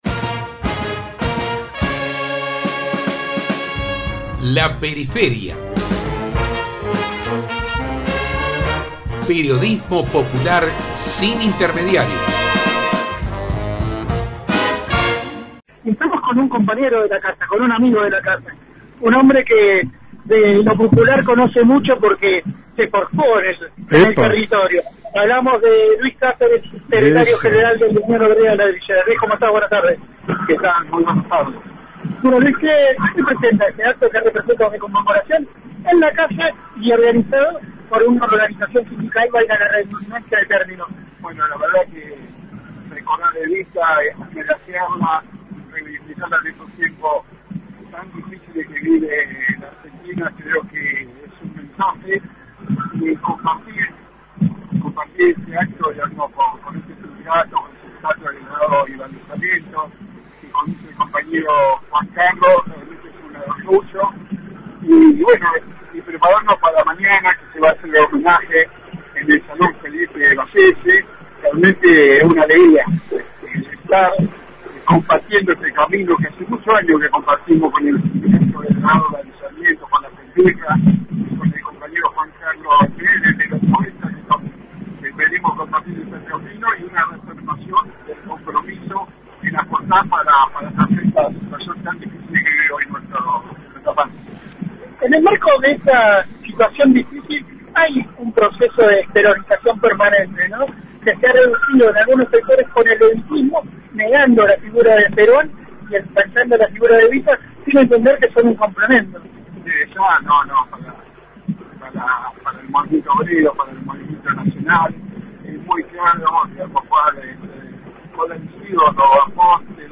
Compartimos la entrevista completa: 25/7/2022